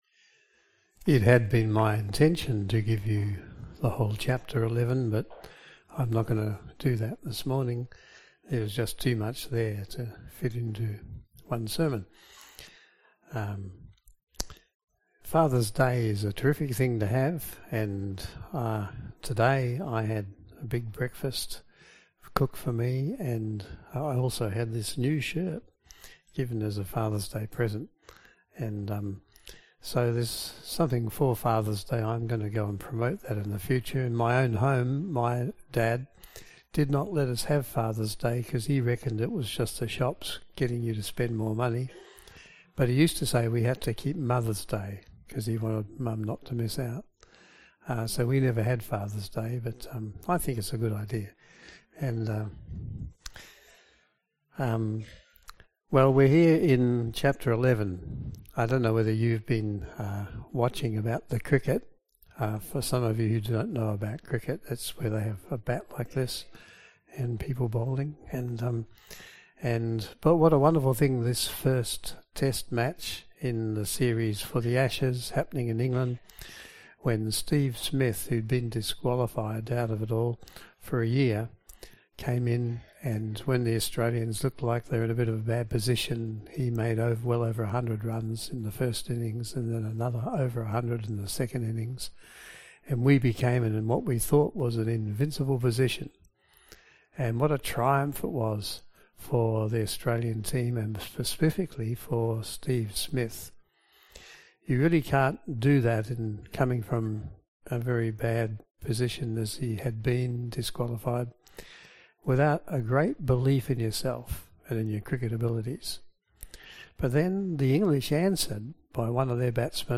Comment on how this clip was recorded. Passage: Hebrews 11:1-4 Service Type: AM Service